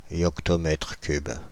Ääntäminen
Ääntäminen France (Île-de-France): IPA: /jɔk.tɔ.mɛtʁ kyb/ Haettu sana löytyi näillä lähdekielillä: ranska Käännöksiä ei löytynyt valitulle kohdekielelle.